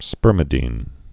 (spûrmĭ-dēn)